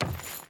SFX / Footsteps / Wood / Wood Chain Run 1.ogg
Wood Chain Run 1.ogg